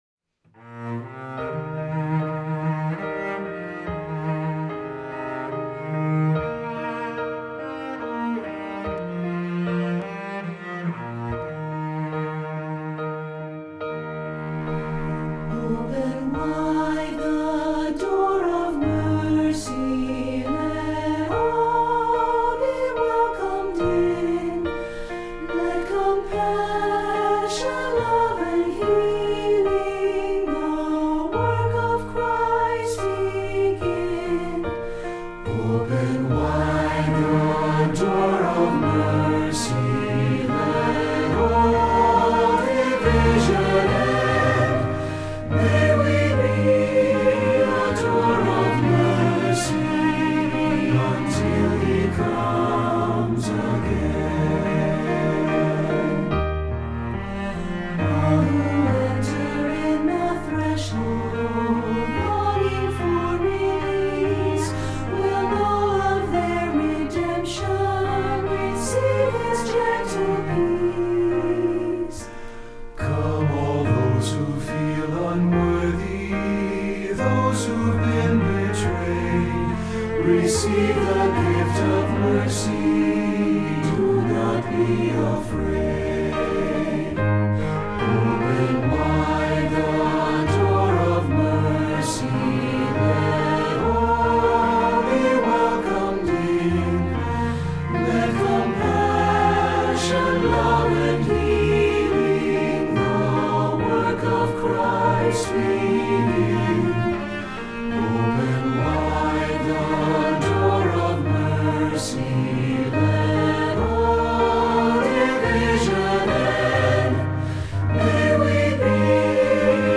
Voicing: SATB and Cello